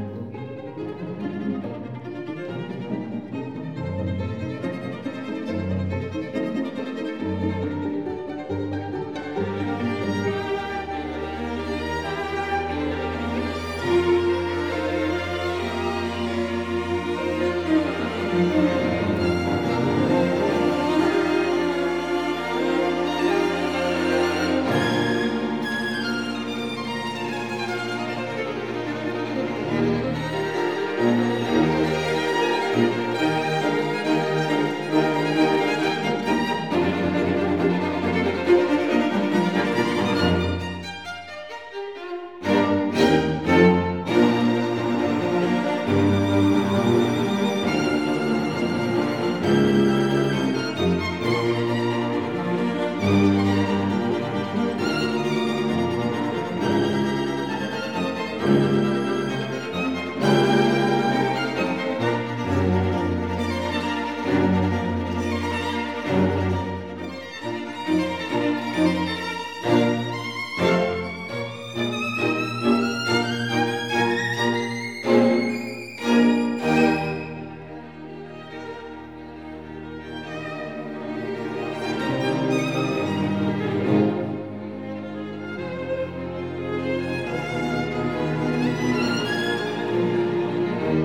但這是一個絕對值得擁有的版本，非常好的演出與錄音。